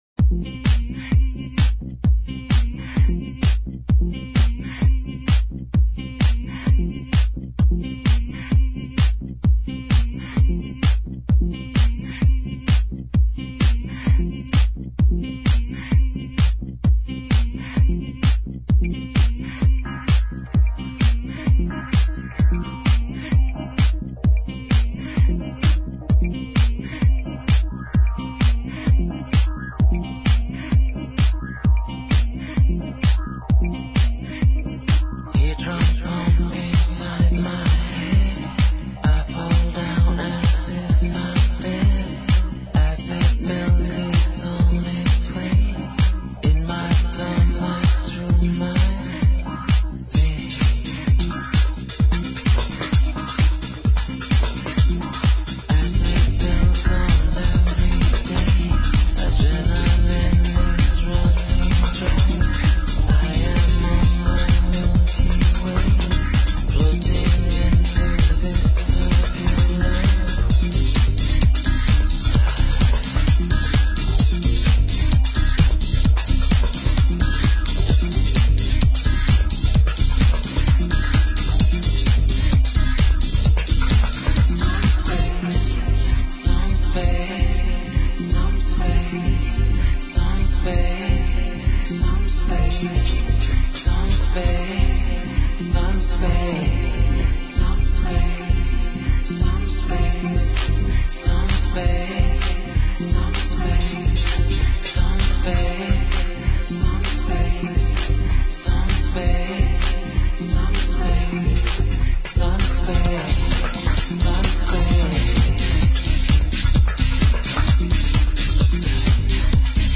Емисија се реализује из Врњачке Бање, са јубиларног 10. Ловефеста.